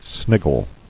[snig·gle]